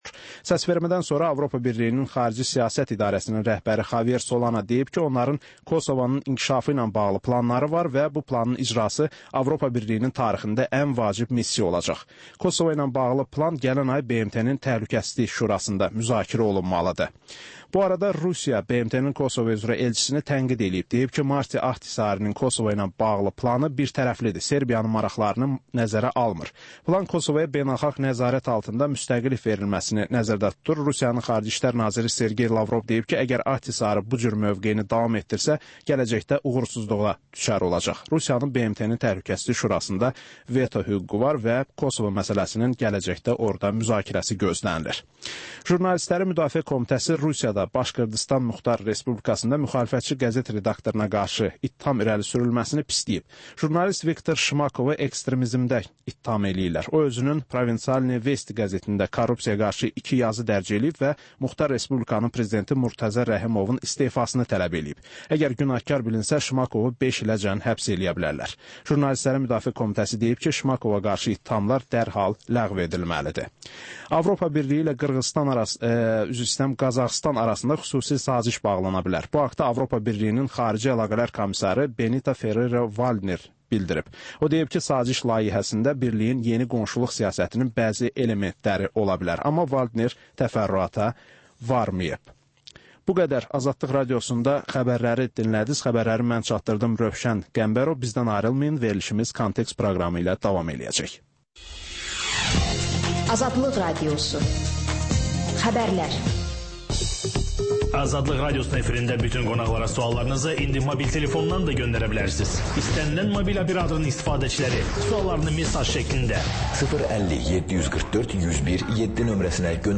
Hadisələrin təhlili, müsahibələr, xüsusi verilişlər.